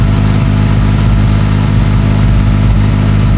Index of /server/sound/vehicles/lwcars/uaz_452
idle.wav